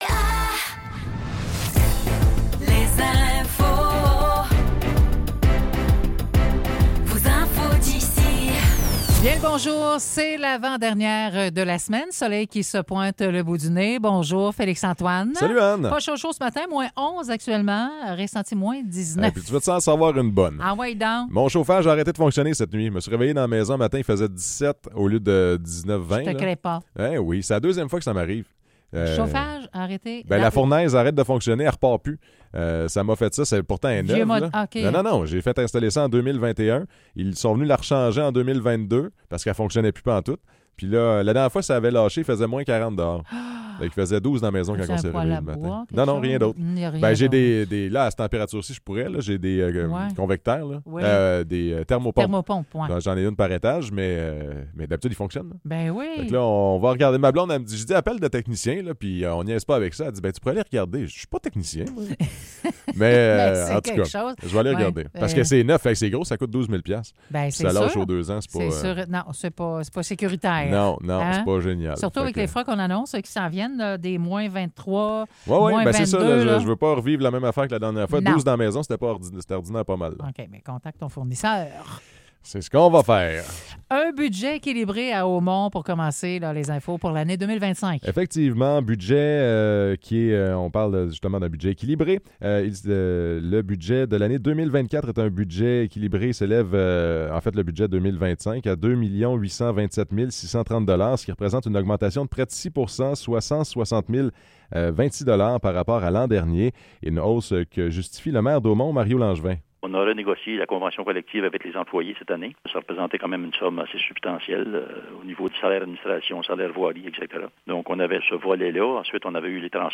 Nouvelles locales - 19 décembre 2024 - 9 h